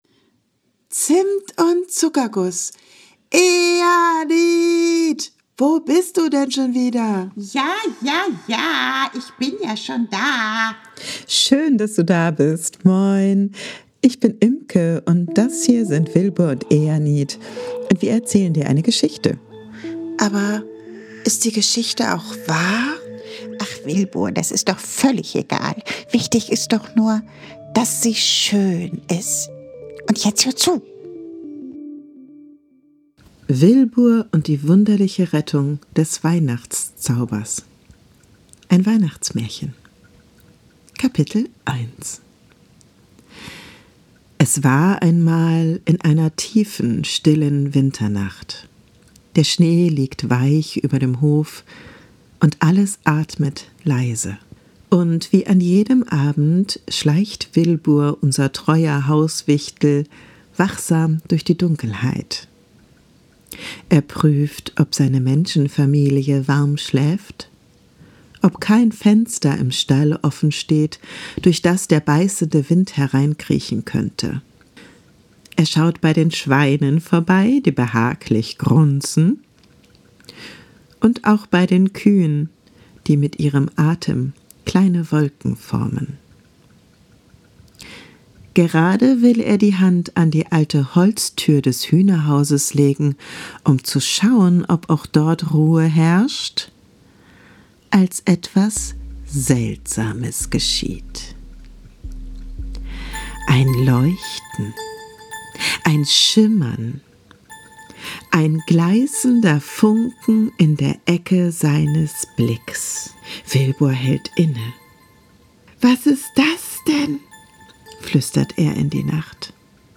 Ein moderner Märchen-Podcast für Kinder